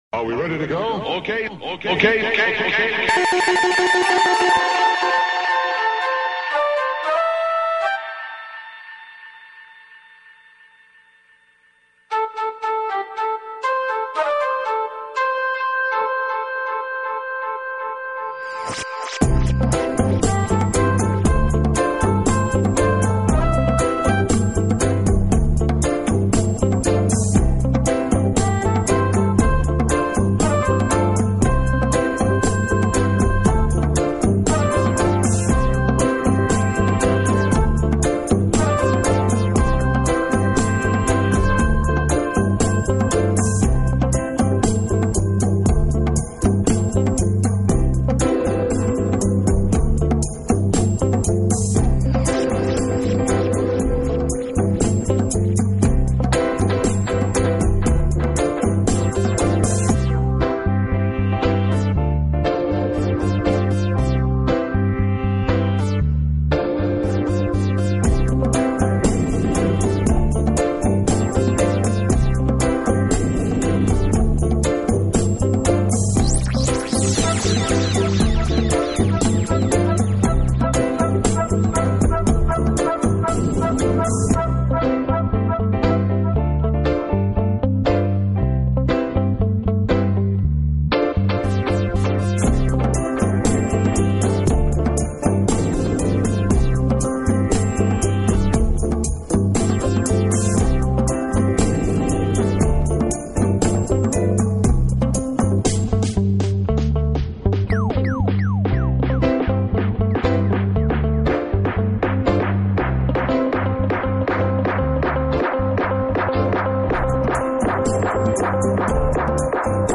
JAPANESE DUB REGGAE